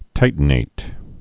(tītn-āt)